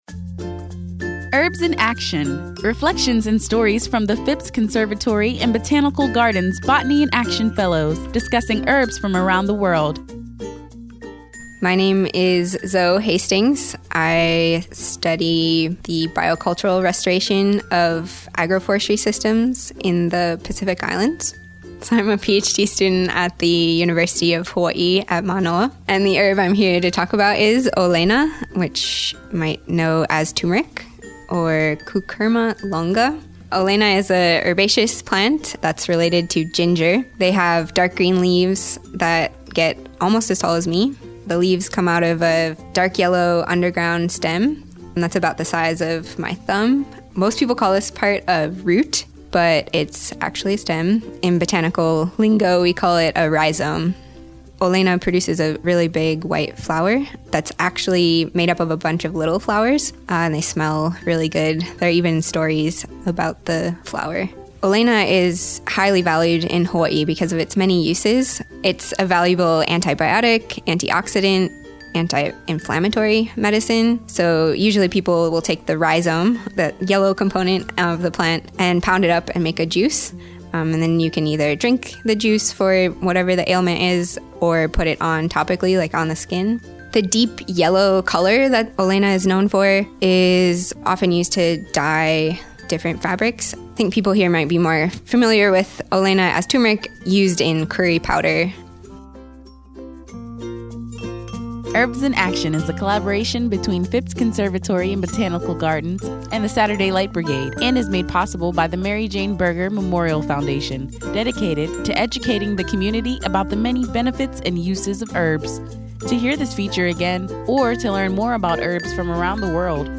Listen as they share their knowledge of and experiences with these herbs as botanists of the world.